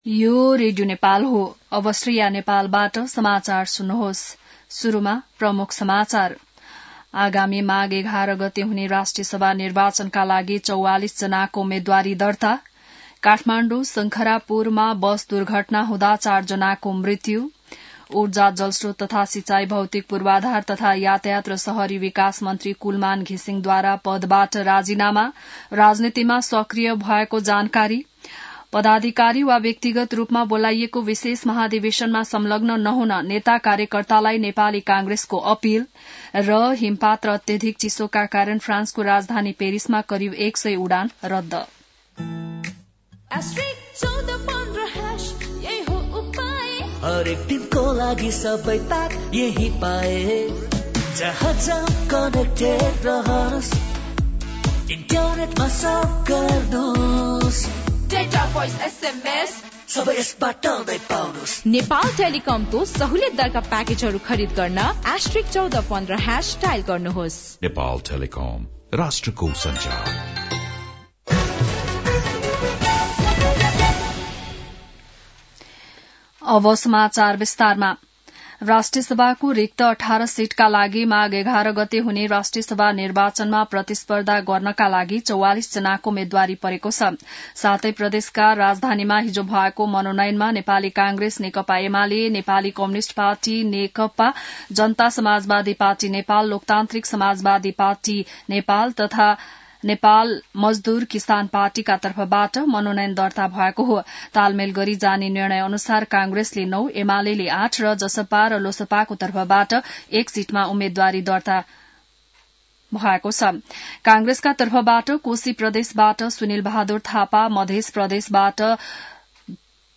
बिहान ७ बजेको नेपाली समाचार : २४ पुष , २०८२